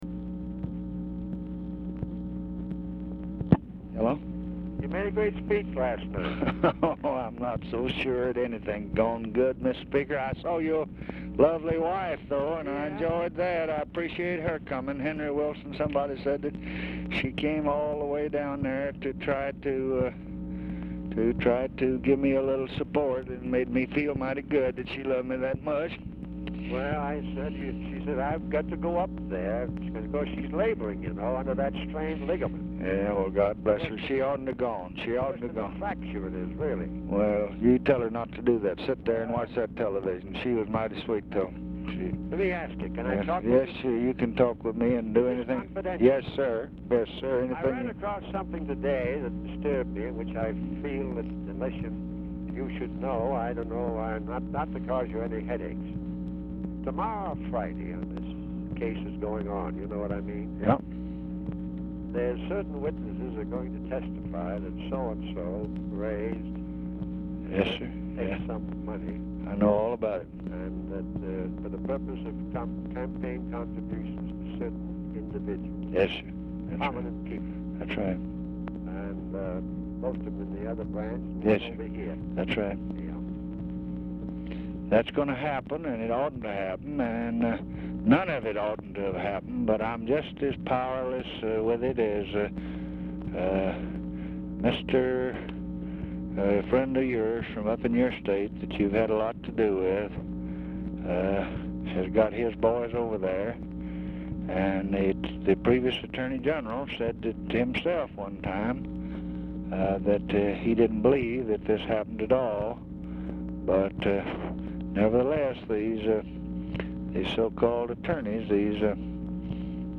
Telephone conversation # 11342, sound recording, LBJ and JOHN MCCORMACK, 1/11/1967, 7:12PM | Discover LBJ
Format Dictation belt
Location Of Speaker 1 Oval Office or unknown location